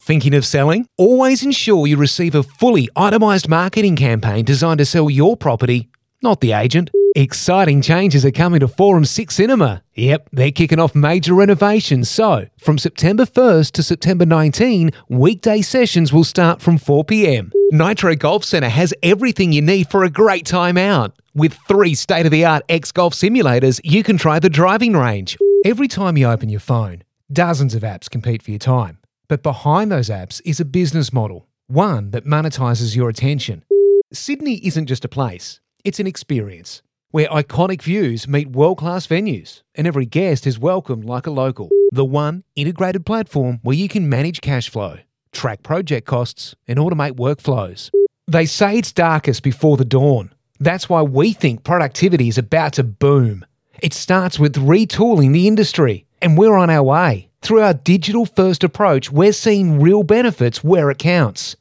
Adult (30-50)